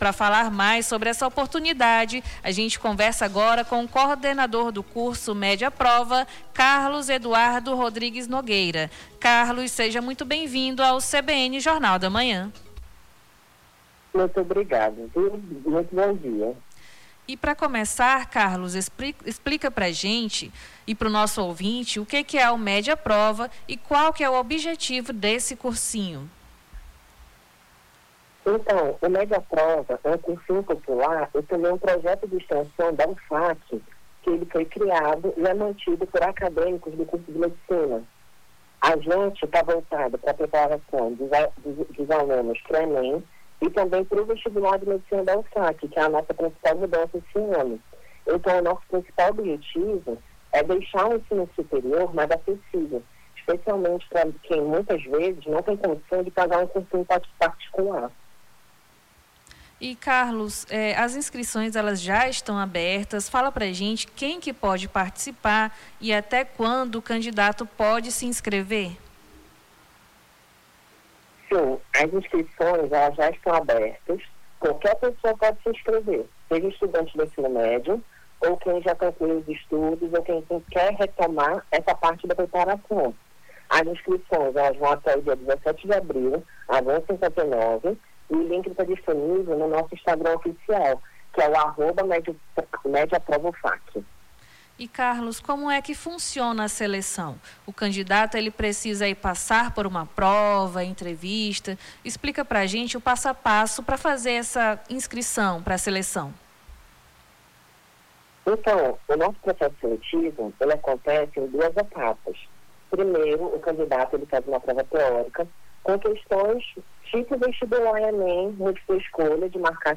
Nome do Artista - CENSURA - ENTREVISTA MED PROVA - 08-04-26.mp3